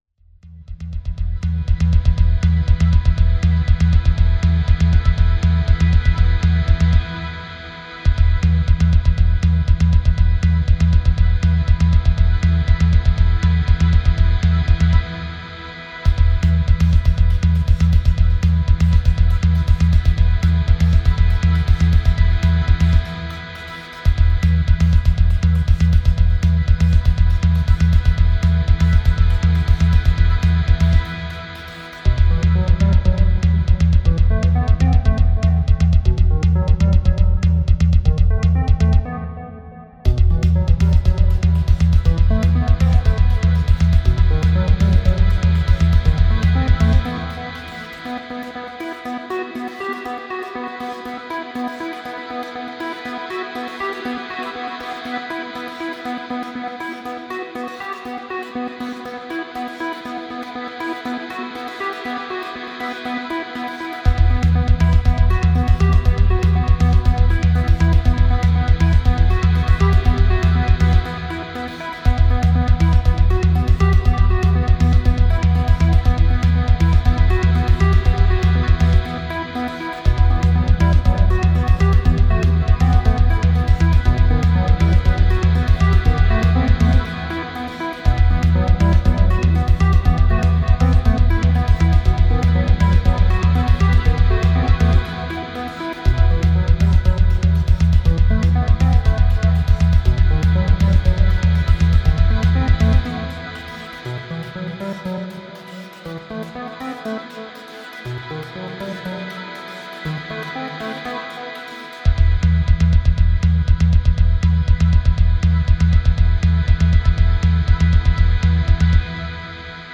ELECTRO S-Z (34)